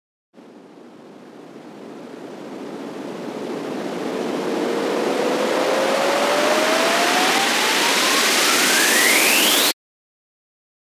FX-1538-RISER
FX-1538-RISER.mp3